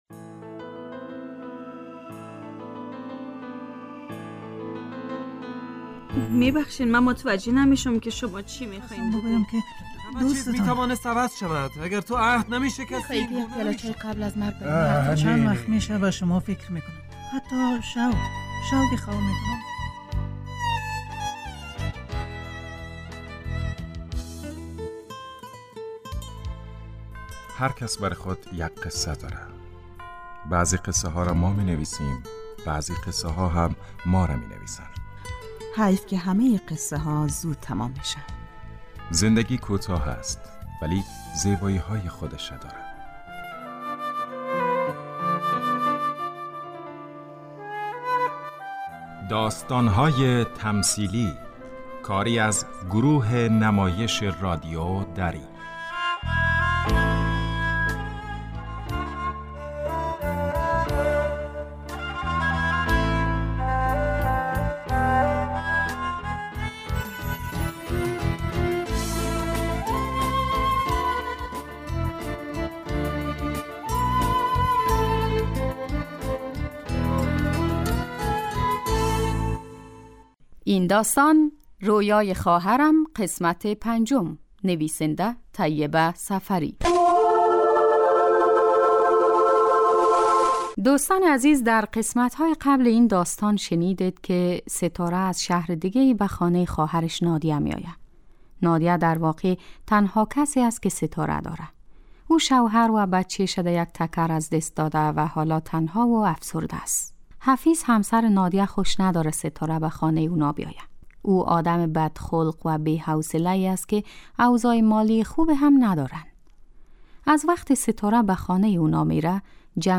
داستان تمثیلی - رویای خواهرم قسمت پنجم